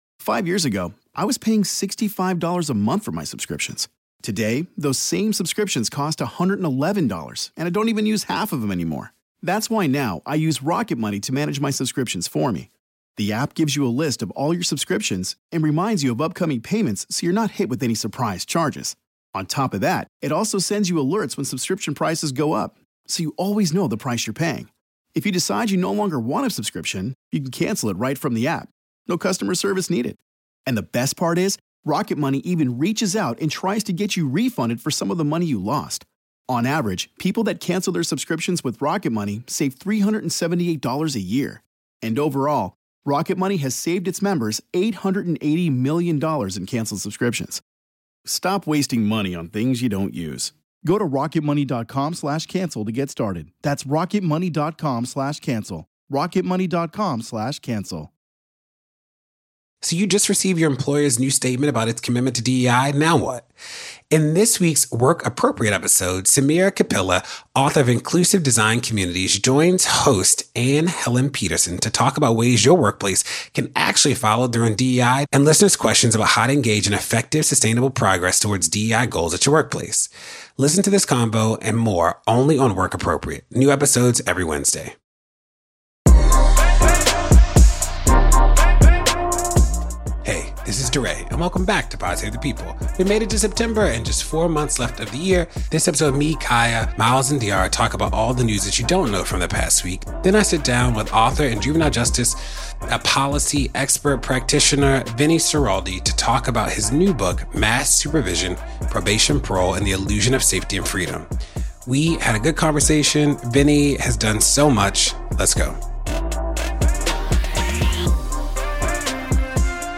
DeRay interviews author and juvenile justice policy reformer Vincent Schiraldi about his new book Mass Supervision: Probation, Parole, and the Illusion of Safety and Freedom.